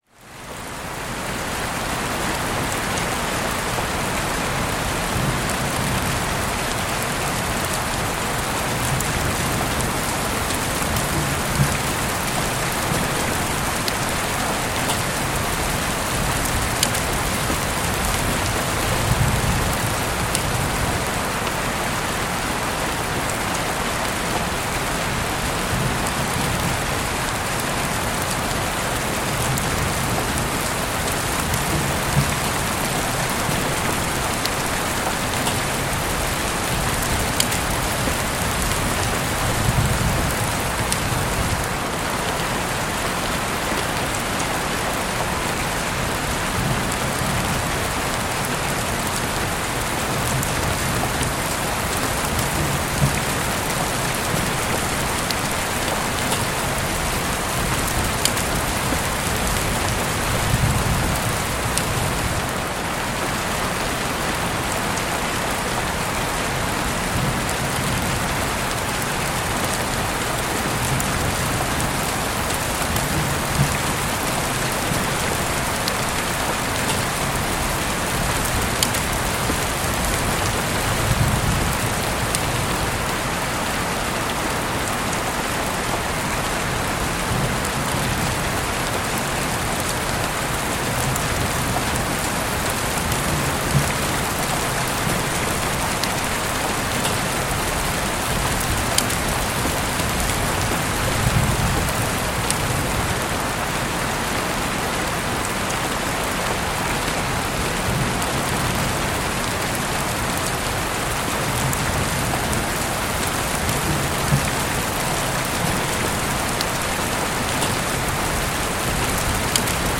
Lluvia por Ventana Abierta para Relajación y una Mente Clara